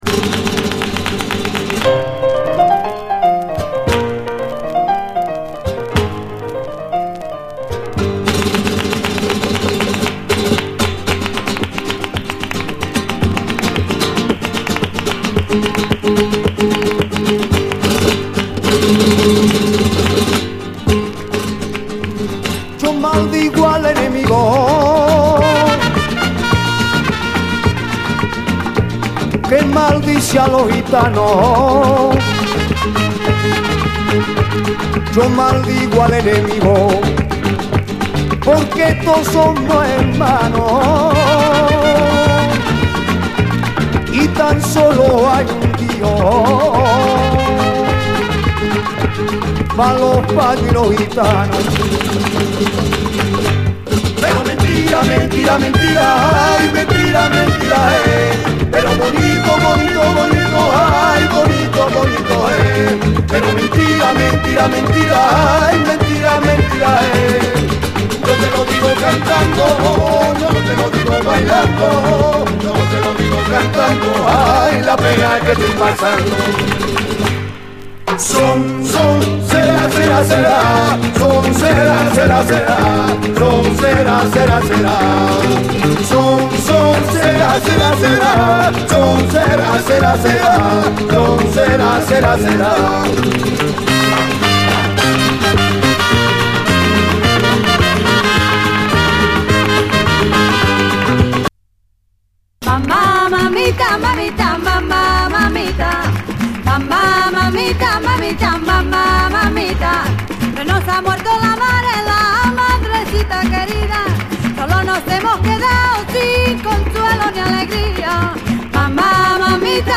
SPANISH, WORLD
オーセンティックでグルーヴィーなルンバ・フラメンカ集！